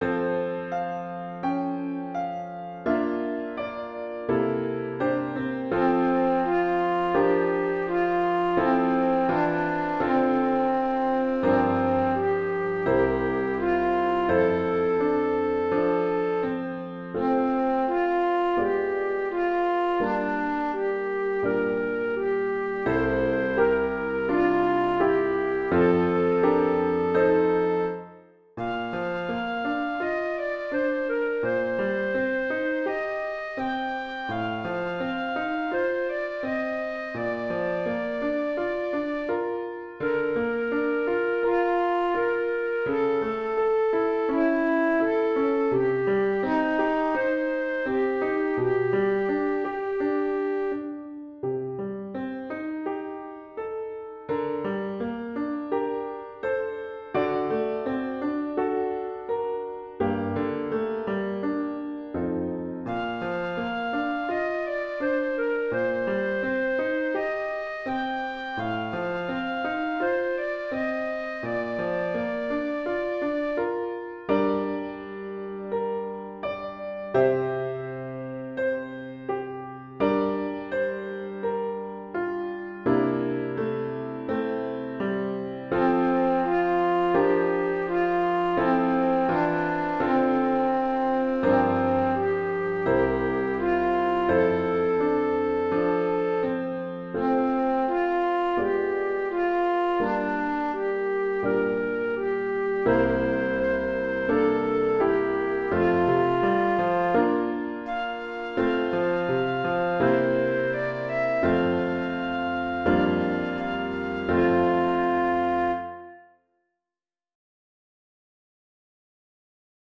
Pour clarinette et piano DEGRE CYCLE 1